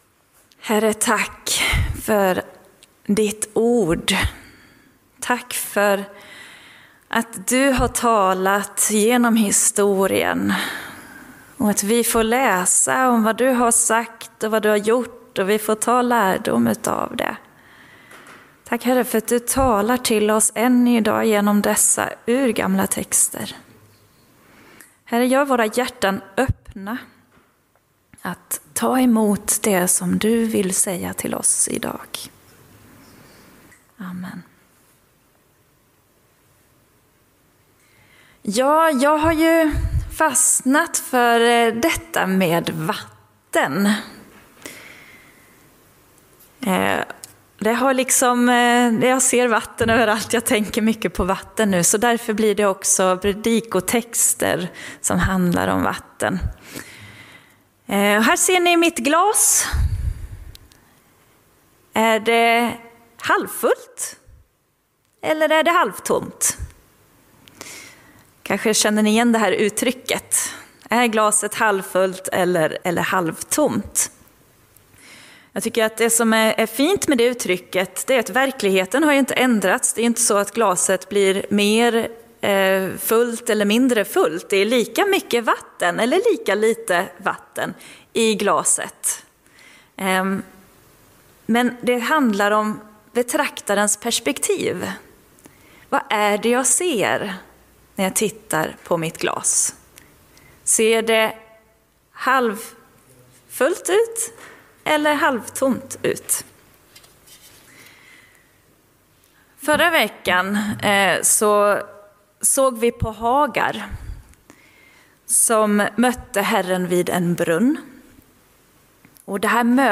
Gudstjänst i Centrumkyrkan i Mariannelund